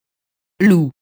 loup [lu]